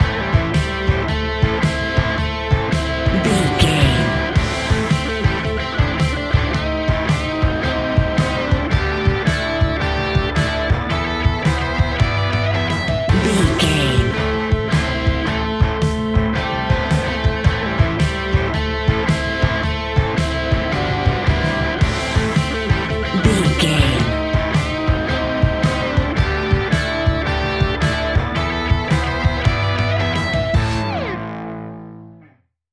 Ionian/Major
hard rock
blues rock
distortion
instrumentals